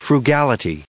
added pronounciation and merriam webster audio
1485_frugality.ogg